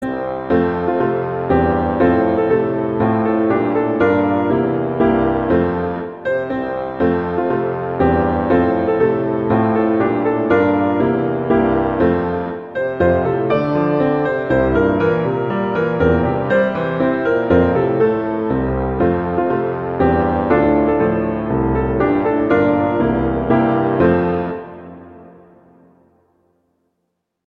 arrangements for tuba in Eb and piano
tuba in Eb and piano